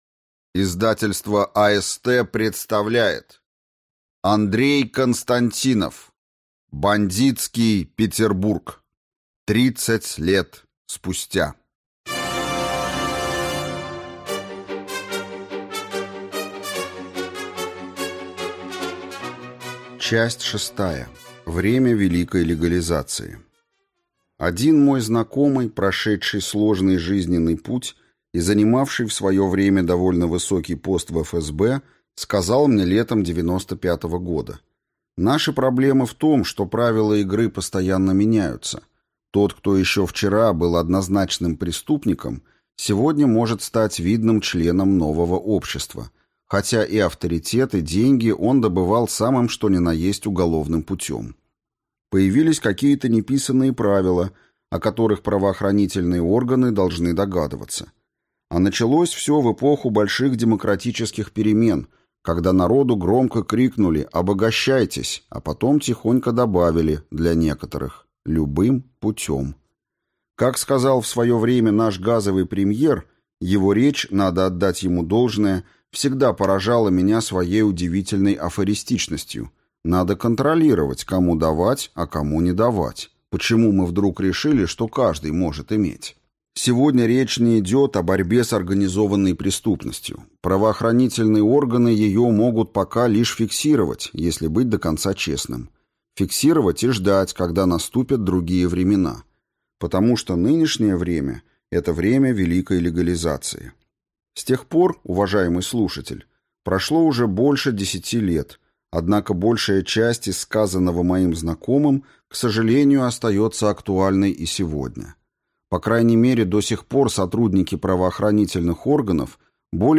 Аудиокнига Бандитский Петербург. Часть шестая. Время великой легализации | Библиотека аудиокниг